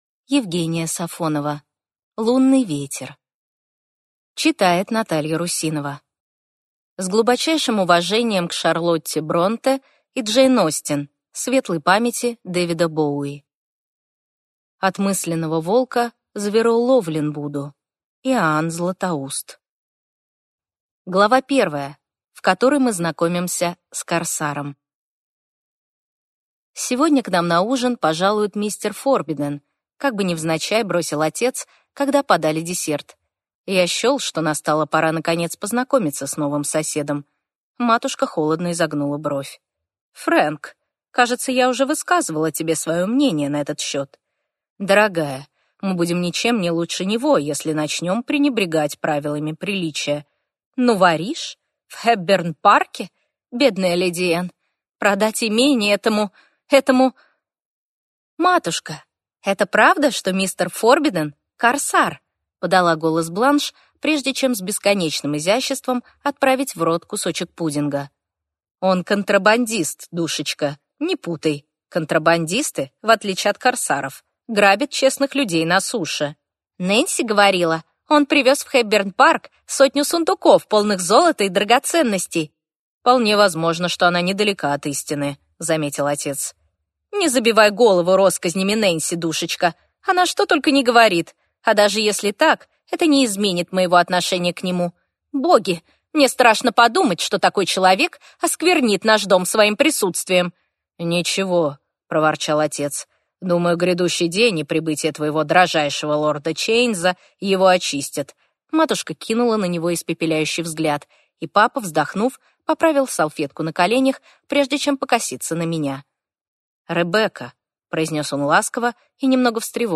Аудиокнига Лунный ветер | Библиотека аудиокниг